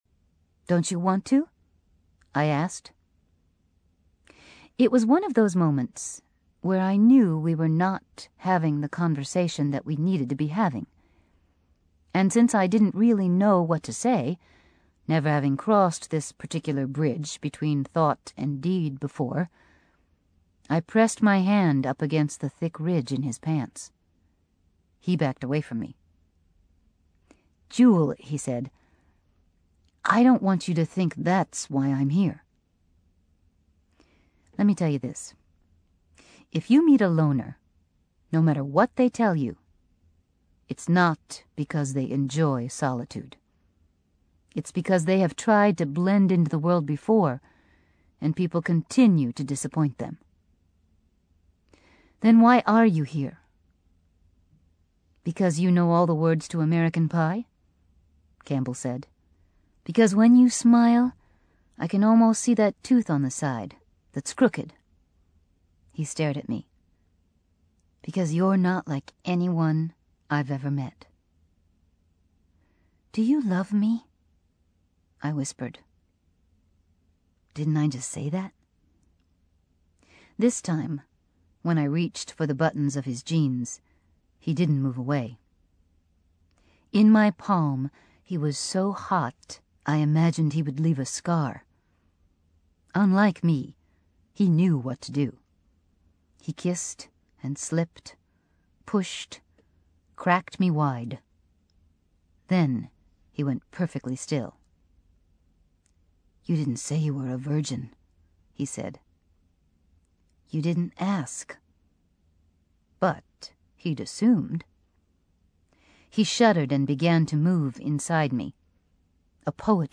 英文广播剧在线听 My Sister's Keeper（姐姐的守护者）65 听力文件下载—在线英语听力室